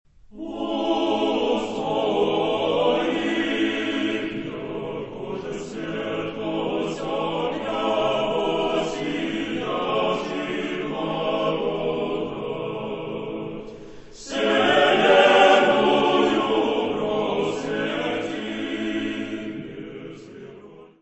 Descrição Física:  1 Disco (CD) (55 min.) : stereo; 12 cm
Área:  Música Clássica